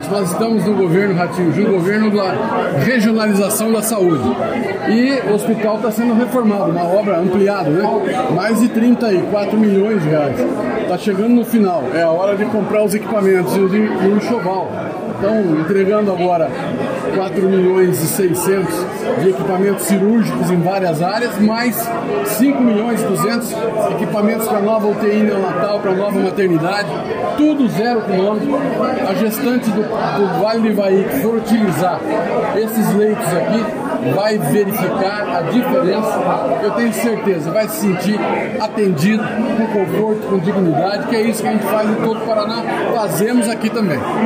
Sonora do secretário da Saúde, Beto Preto, sobre repasse de R$ 9,8 milhões para Hospital da Providência, em Apucarana